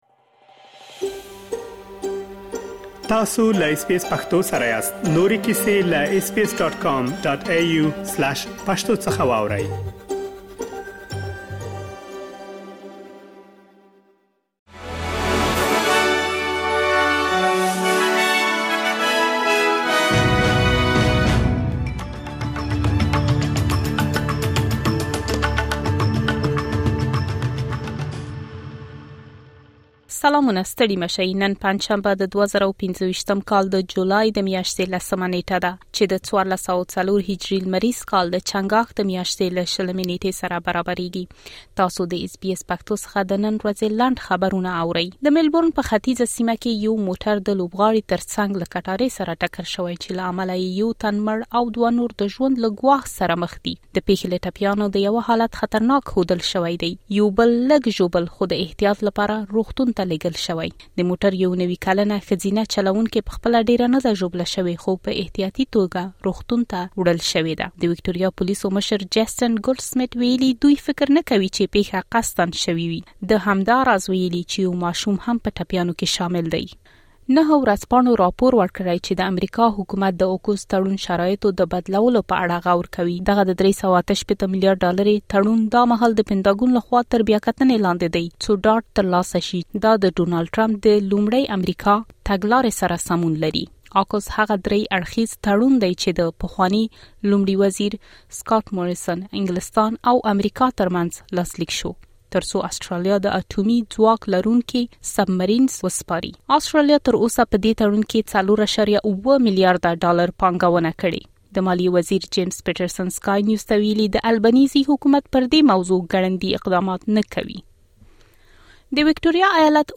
د اس بي اس پښتو د نن ورځې لنډ خبرونه |۱۰ جولای ۲۰۲۵